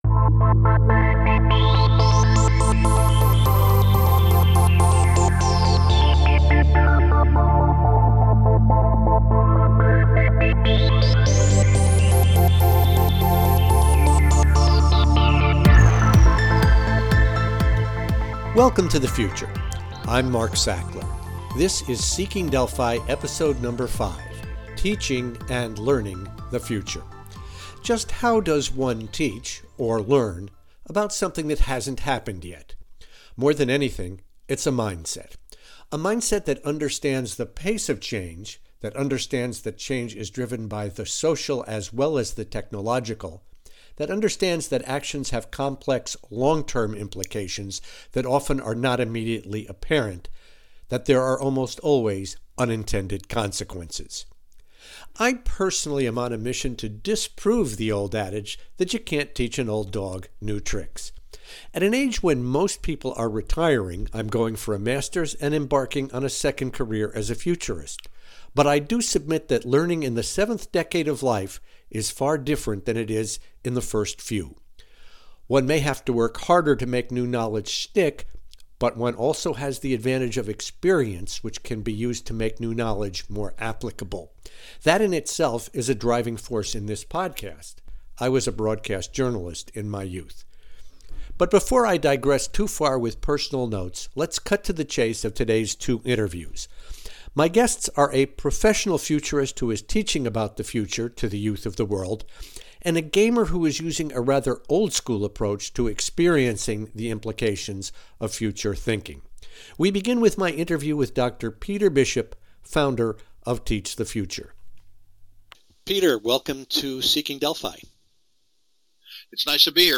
It makes sense, though, that teaching something as fluid, changing and uncertain as the future requires creative tools to involve the student and develop the appropriate mindset. In episode #5 I talk with two individuals who are taking different approaches to the task.